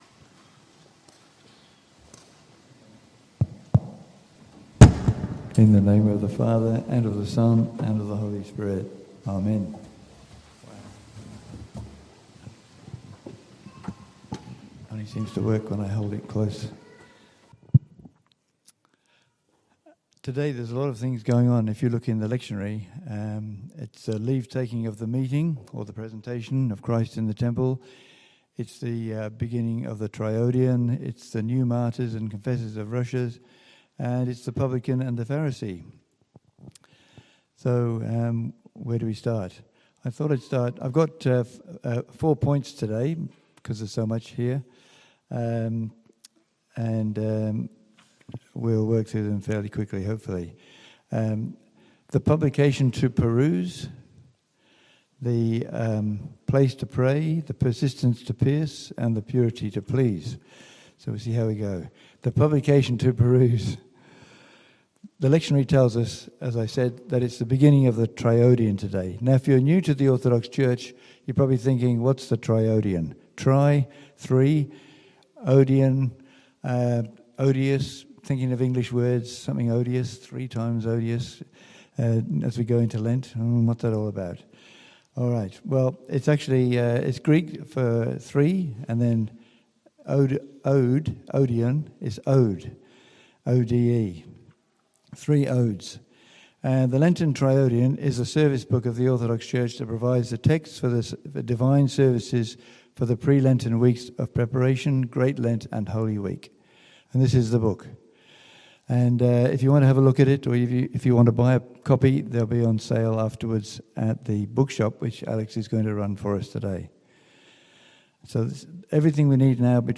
The Good Shepherd Orthodox Church: The Good Shepherd Orthodox Church: Sermons